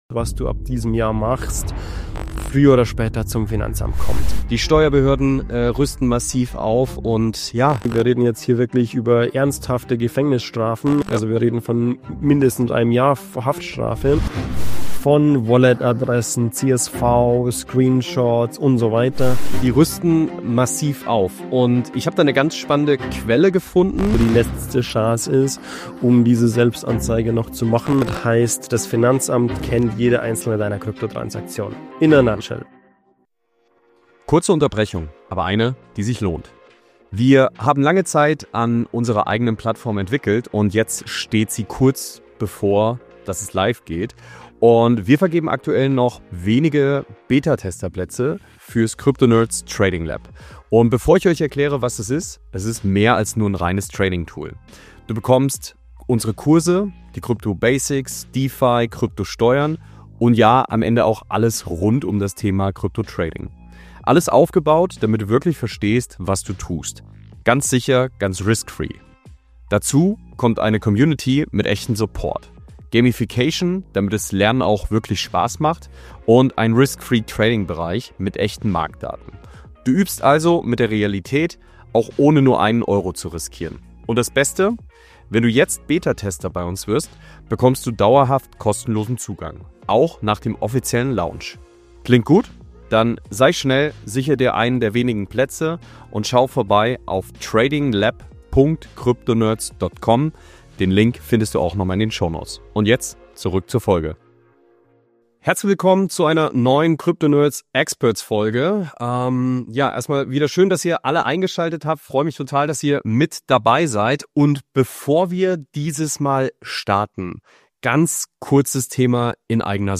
Experts | DAC8, Selbstanzeige & Steuerfalle 2026: Wird es jetzt ernst für Krypto-Anleger? | Interview